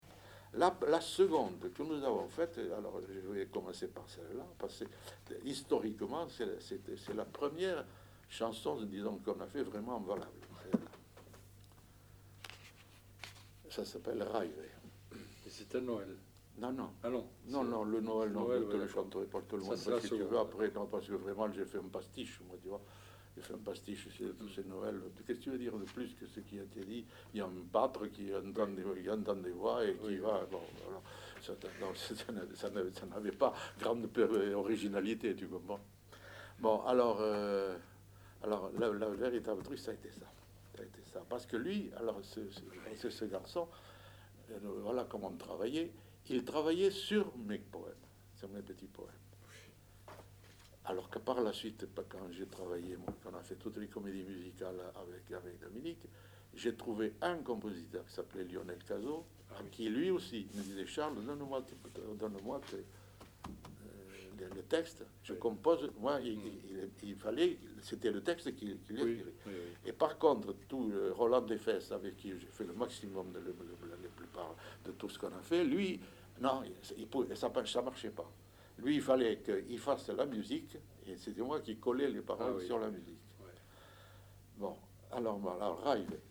Aire culturelle : Rouergue
Lieu : Saint-Sauveur
Genre : chant
Effectif : 1
Type de voix : voix d'homme
Production du son : chanté